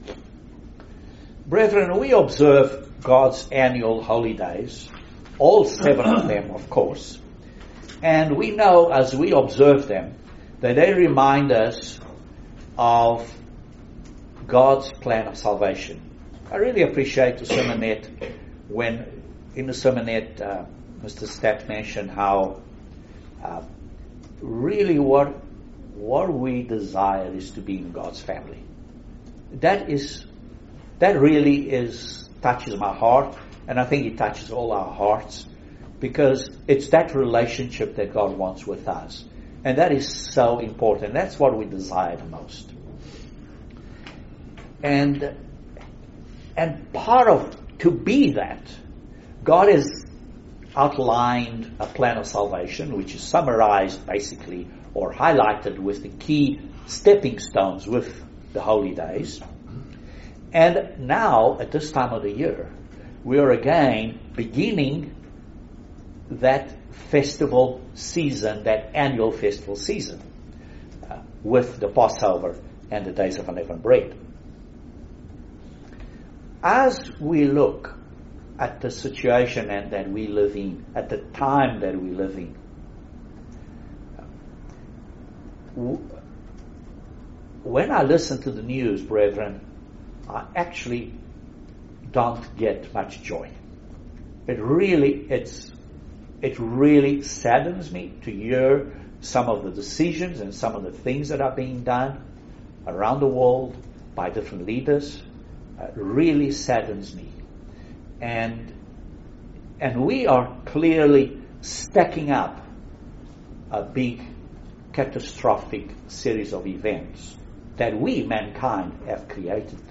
Join us for this excellent video sermon on the Passover and Days of Unleavened Bread.